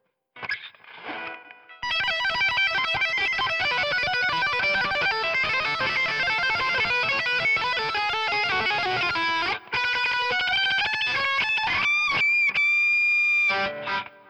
Hi, ich lerne in letzer Zeit ( oder versuche es zumindest ) ein paar schnelle Gitarrensolos, nur ich bekomme keinen vernünftigen Ton hin, matscht zu sehr, klingt trotzdem recht dünn und ist generell unhörbar.
Der Amp wurde direkt per USB aufgenommen nicht mit Mikrofon (Anhang): Anhänge one.mp3 561,6 KB · Aufrufe: 364